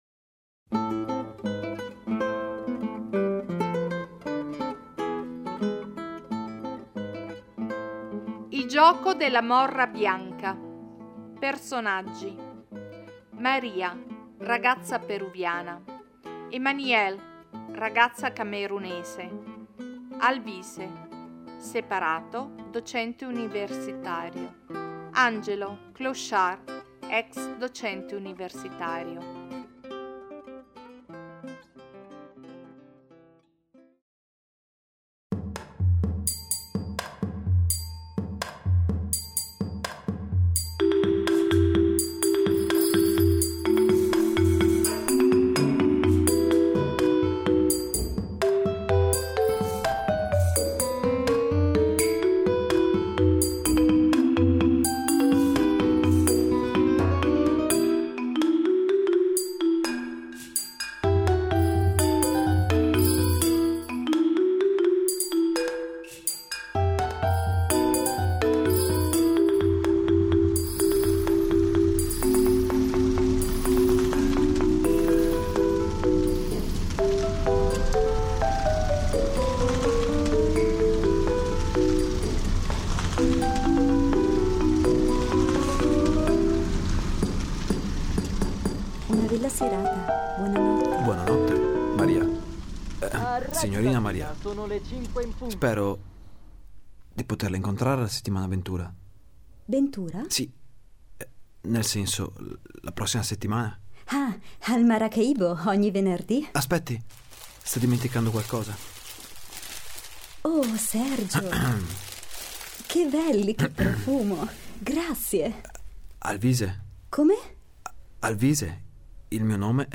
Radiodrammi